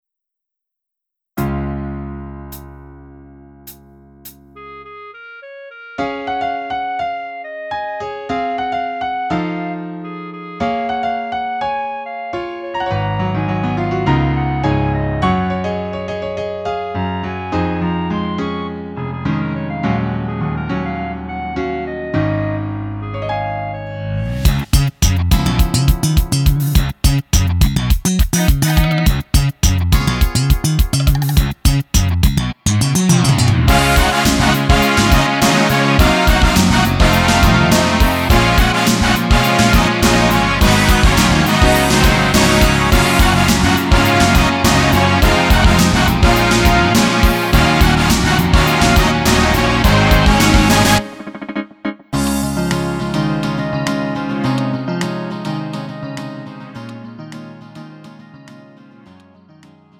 음정 -1키 3:16
장르 가요 구분